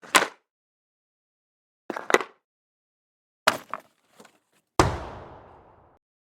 Hockley Puck Noises
SFX
yt_bQlhXLiv888_hockley_puck_noises.mp3